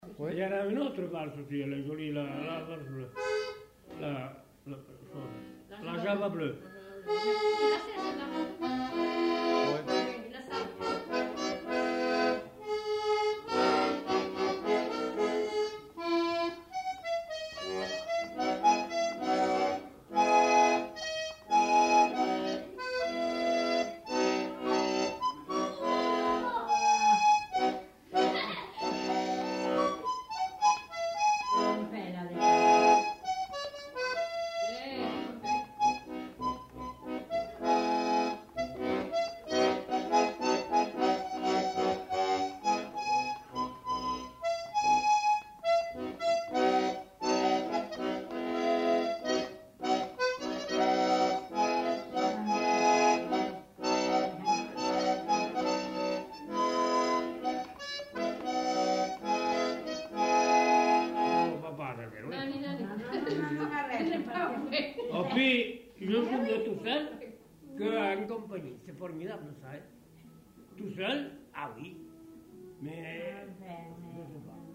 Genre : morceau instrumental
Instrument de musique : accordéon diatonique
Danse : valse
Ecouter-voir : archives sonores en ligne